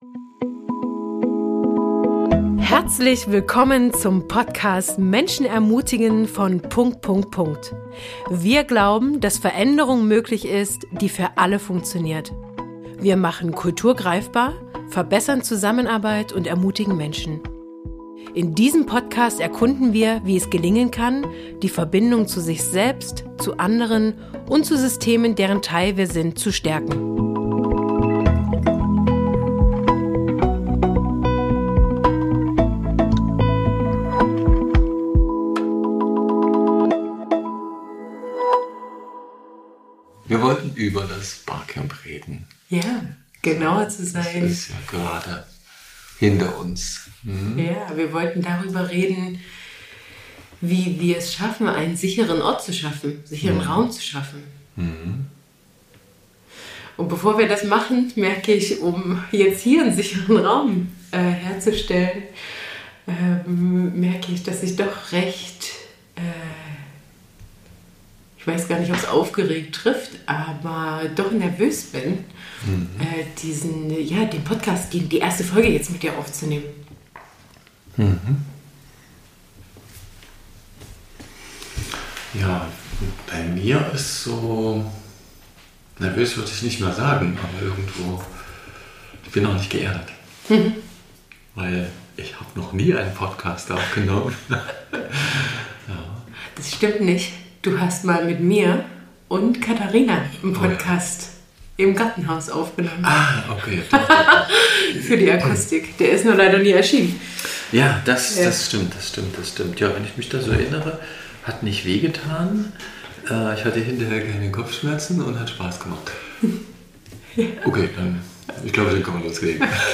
P.S. Die Tonqualität wird sich in der nächsten Folge verbessern.